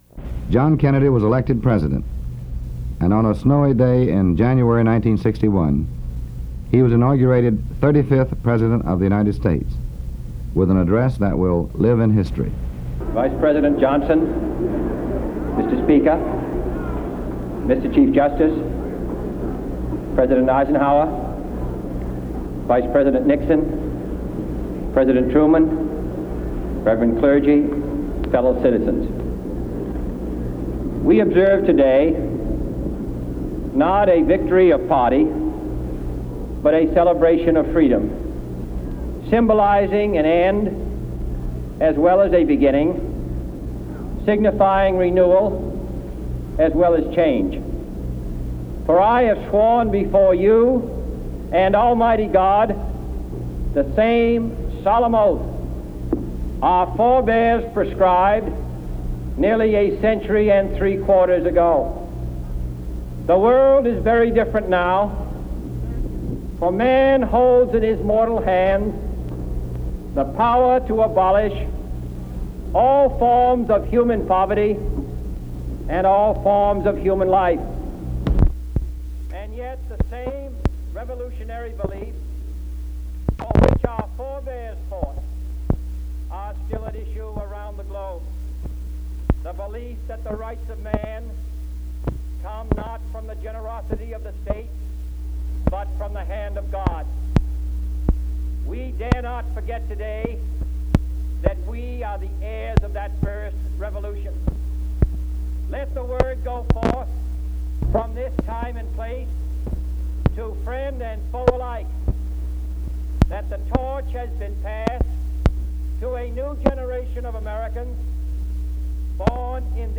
1960 inauguration speech